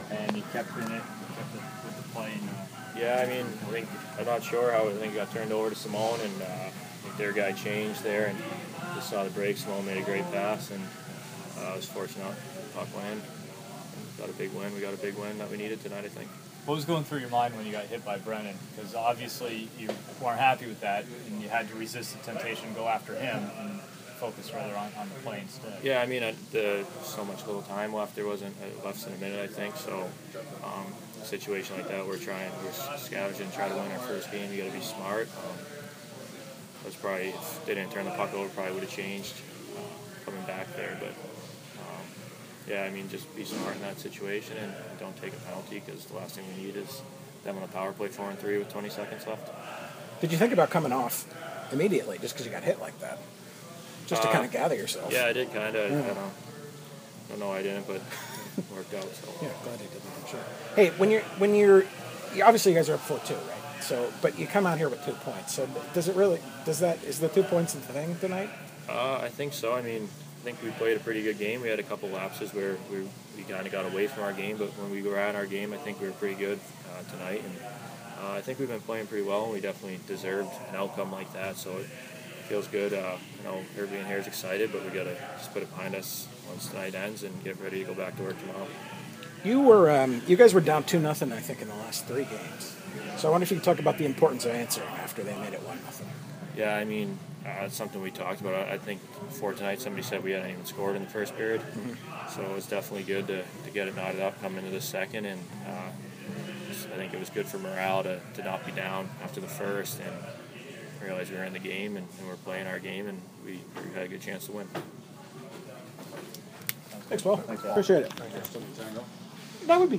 As part of Social Media Night with the WBS Penguins, they gave bloggers access to press row and locker room post game.